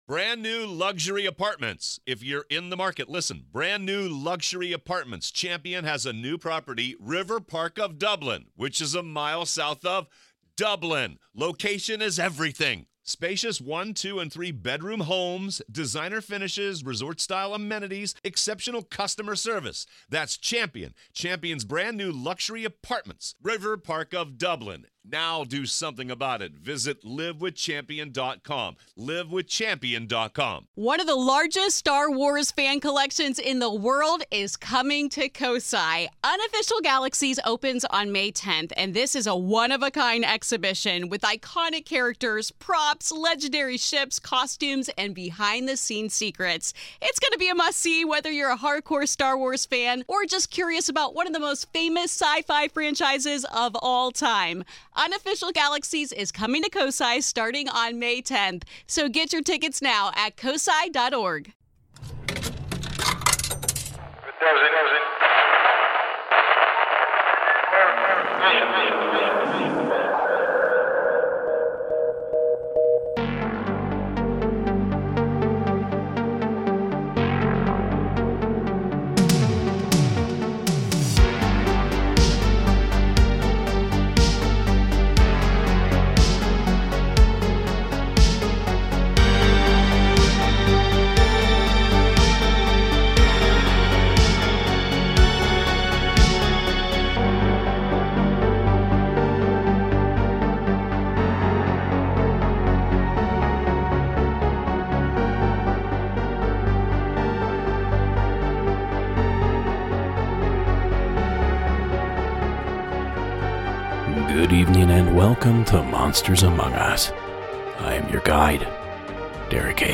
Season 19 Episode 36 of Monsters Among Us Podcast, true paranormal stories of ghosts, cryptids, UFOs and more, told by the witnesses themselves.